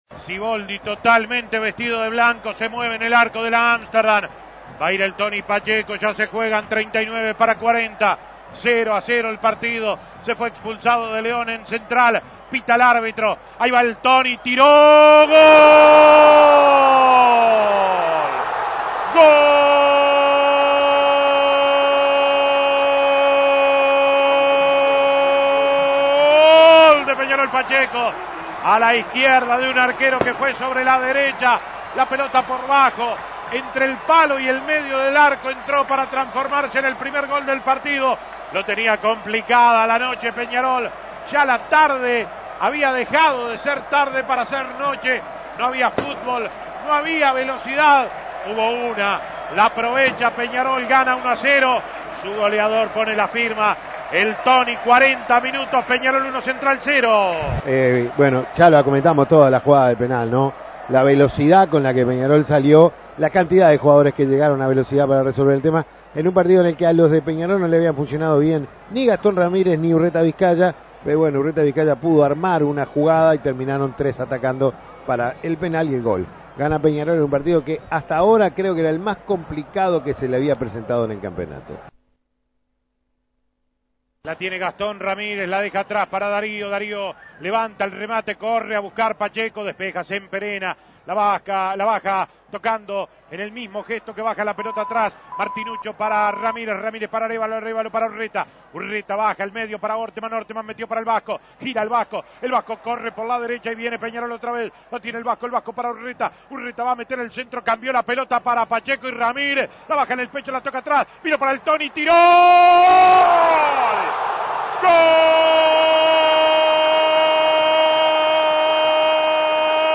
Goles y comentarios ESCUCHE LOS GOLES DE PEÑAROL ANTE CENTRAL ESPAÑOL Imprimir A- A A+ Peñarol sumó su octava victoria consecutiva y sigue cómodo en la punta del Clausura.